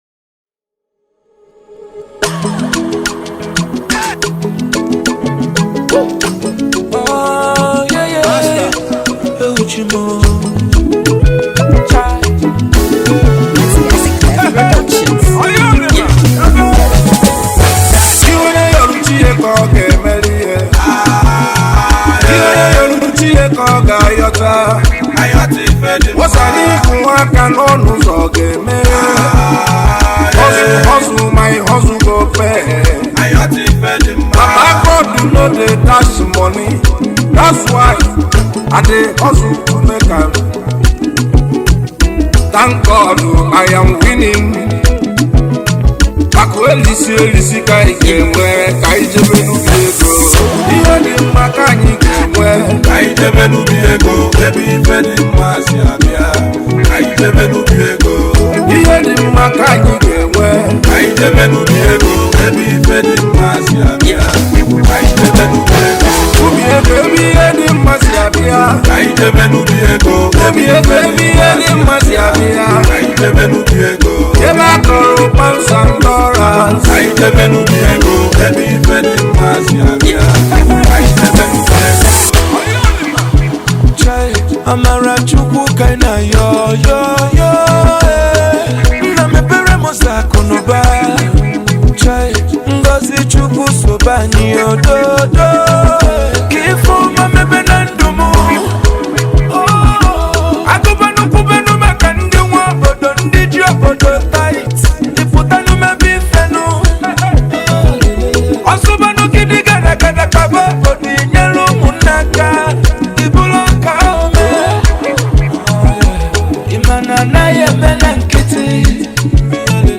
Home » Bongo » Highlife » Ogene